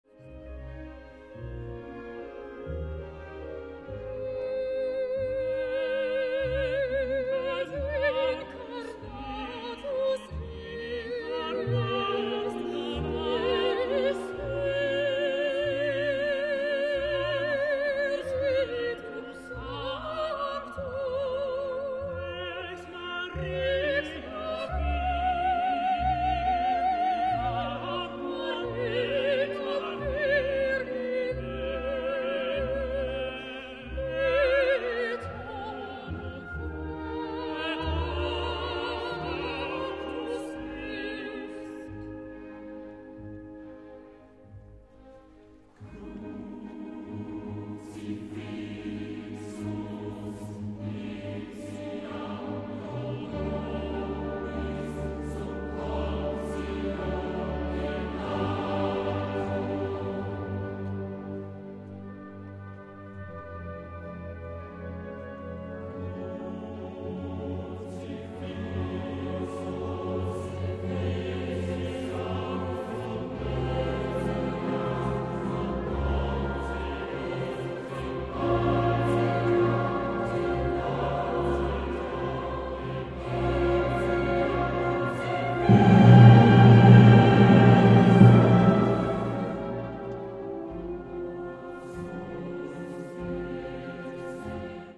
Die Tonqualität entspricht nicht der von CD.
Kirchenmusik an der Basilika St. Jakob, Straubing: